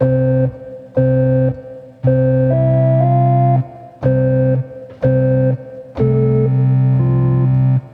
This was my 9th day recording on this found organ.
day09_instrumental_endLoop.wav